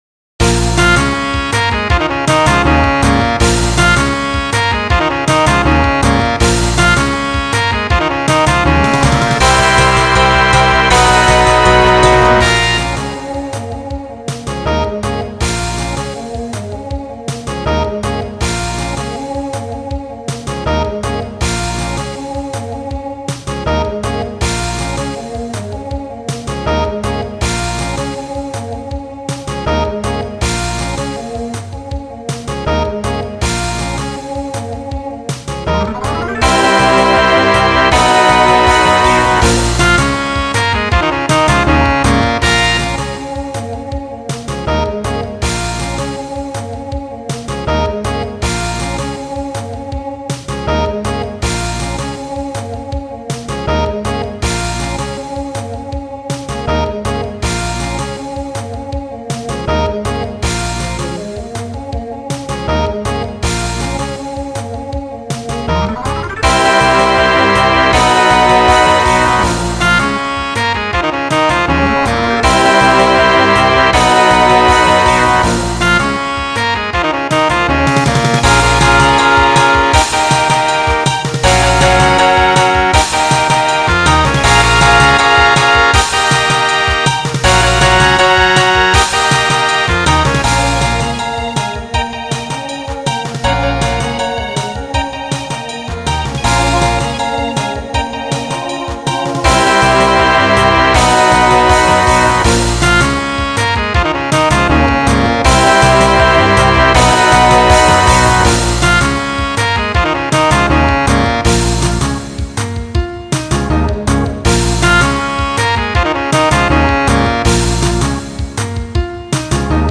Voicing TTB Instrumental combo Genre Pop/Dance